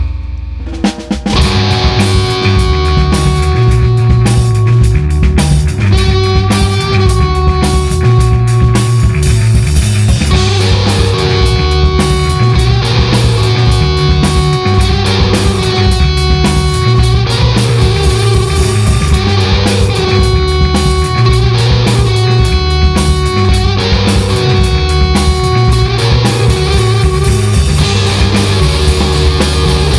alternative-rock band